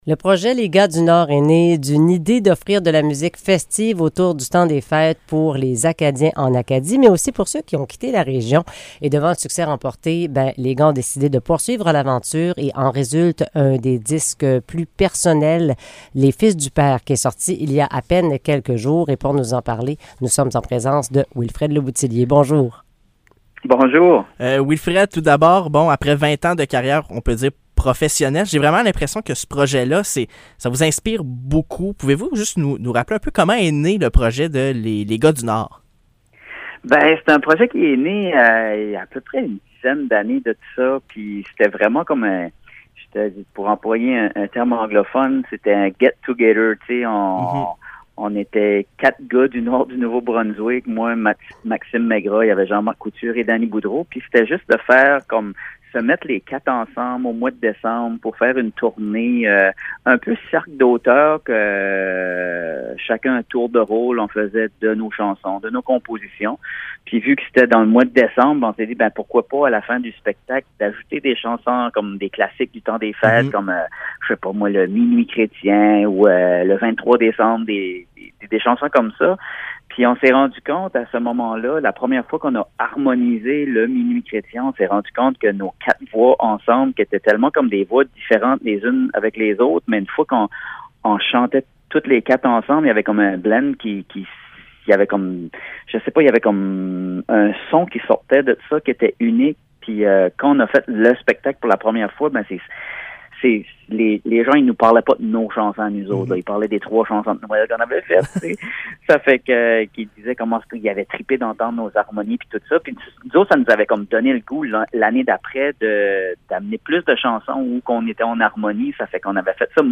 Entrevue avec le chanteur et co-réalisateur, Wilfred Le Bouthillier